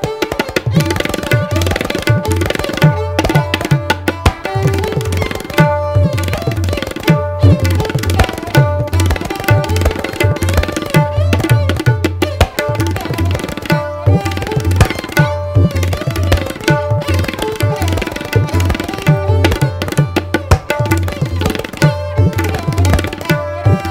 Tabla Ringtones